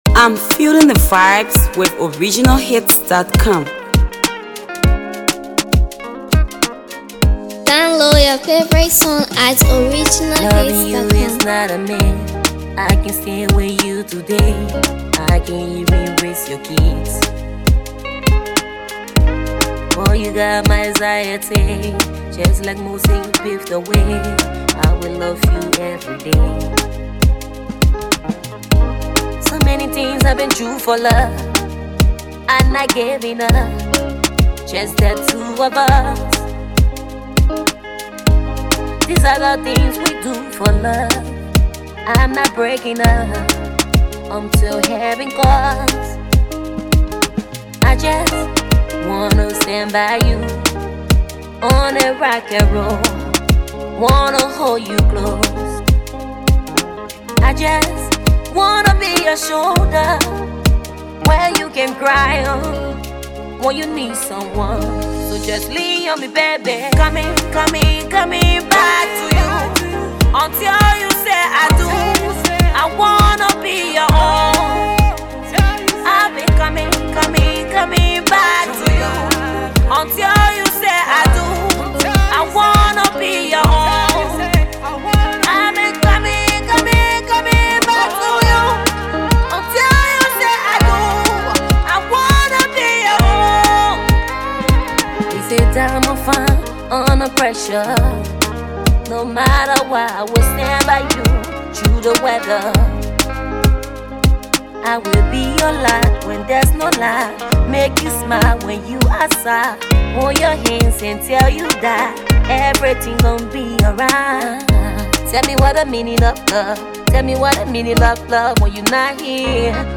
Emotional New Single
With her soothing vocals and keen sense of storytelling